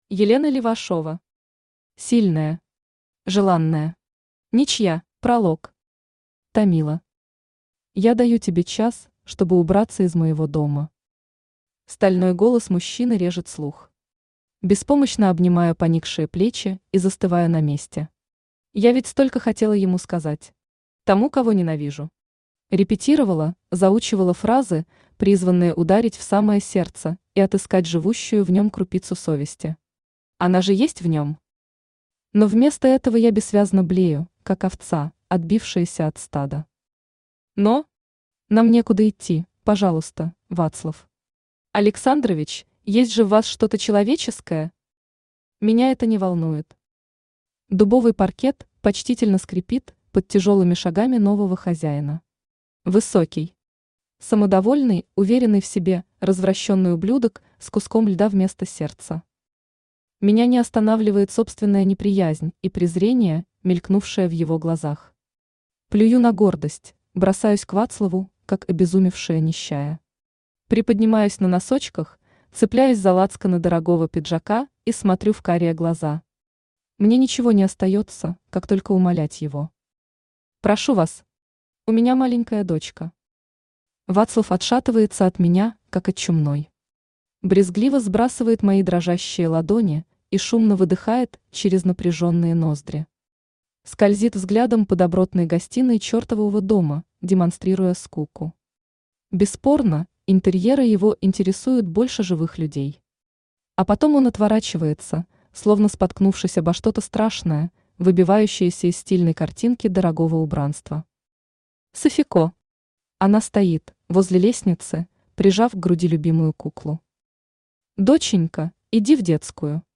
Аудиокнига Сильная. Желанная. Ничья | Библиотека аудиокниг
Aудиокнига Сильная. Желанная. Ничья Автор Елена Левашова Читает аудиокнигу Авточтец ЛитРес.